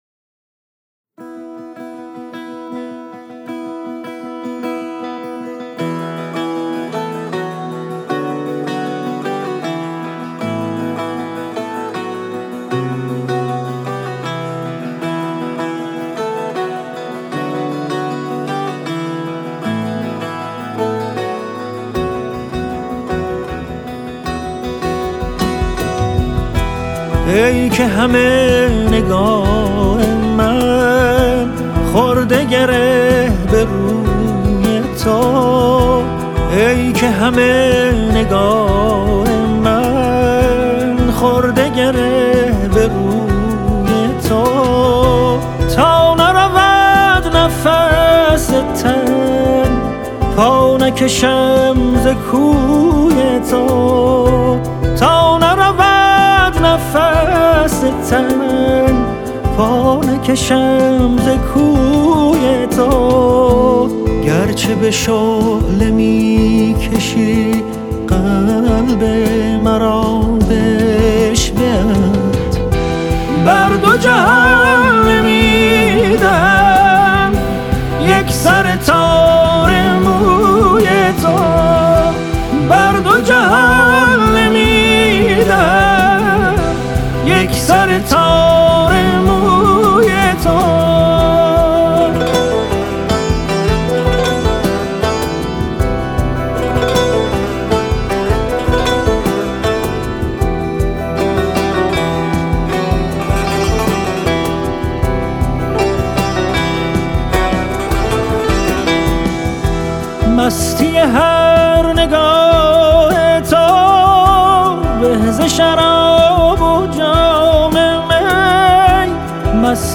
تیتراژ سریال